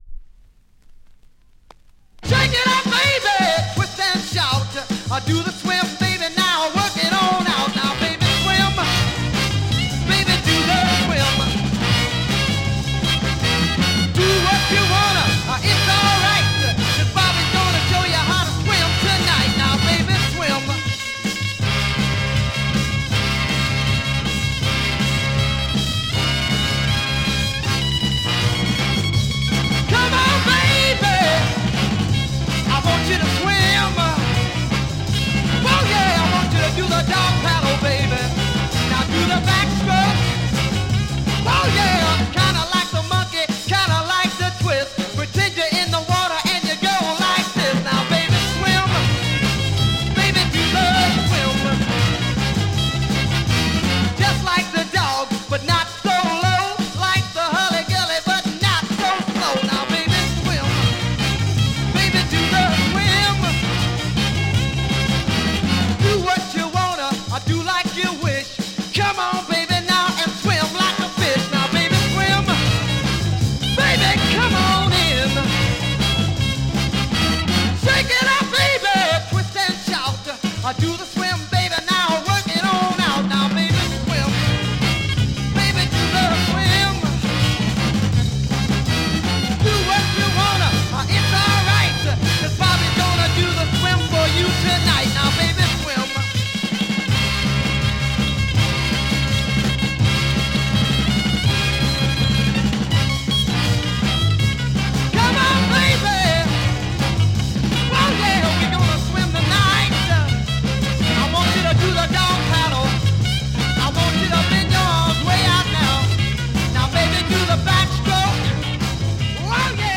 Killer R&B Soul smasher Mod EP!
Mega rare French EP issue, Killer R&B Mod smasher!!